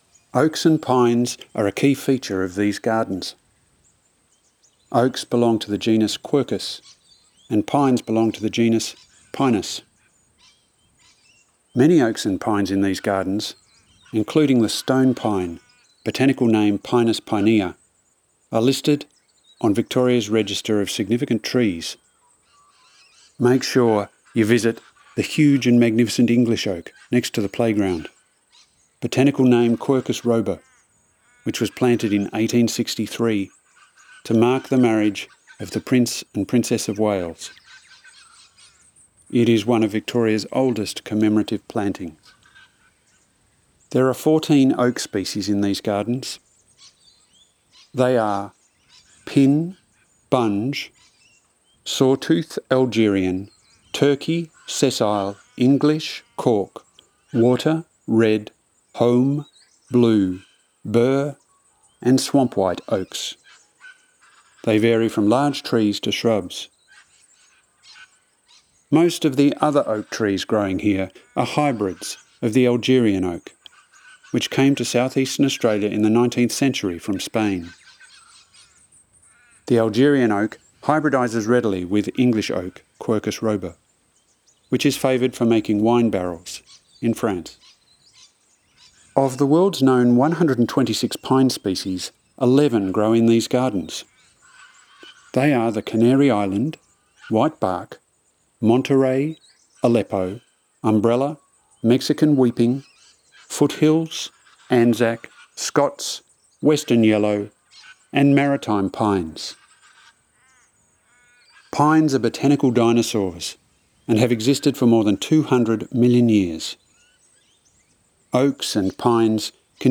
Audio tour
cbg-audio-guide-oaks-and-pines.wav